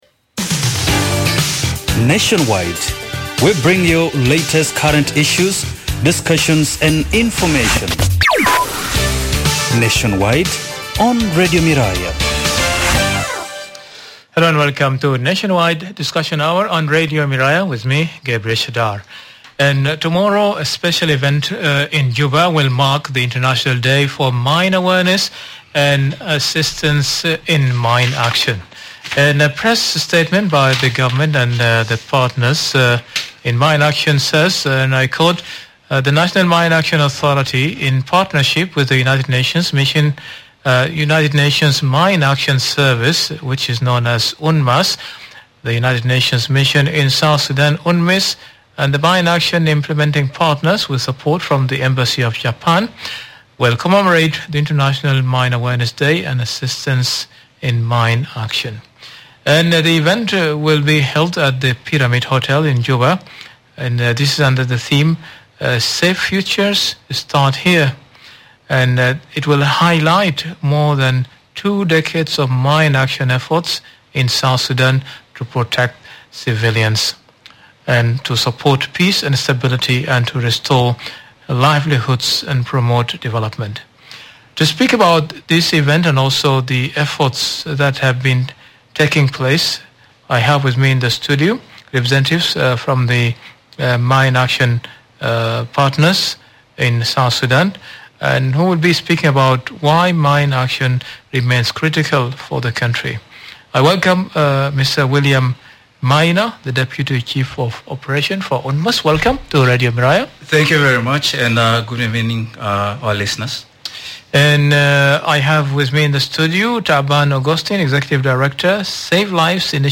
They also highlight the collaborative efforts in mine action, including risk education, data collection, and clearing hazardous areas. To round off the discussion, Radio Miraya listeners call in to share their views, raise concerns, and ask critical questions about safety and broader mine action initiatives.